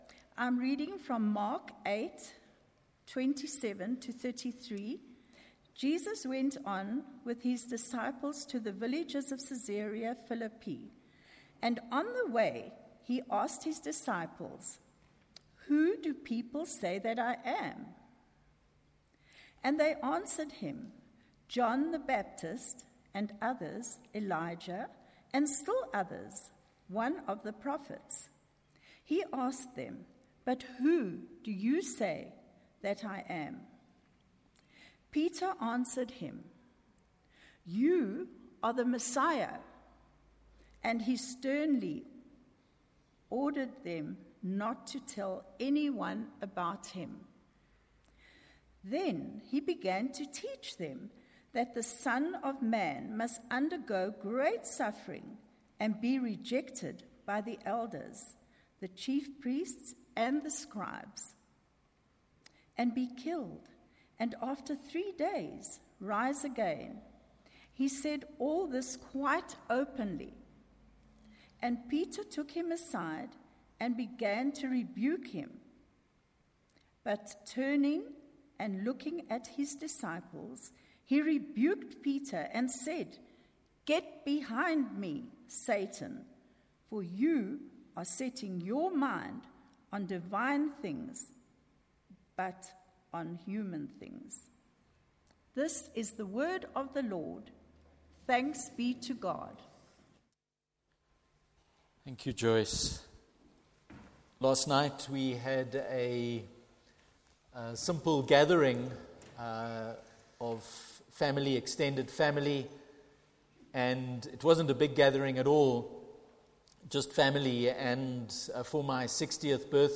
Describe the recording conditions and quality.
Fusion Service from Trinity Methodist Church, Linden, Johannesburg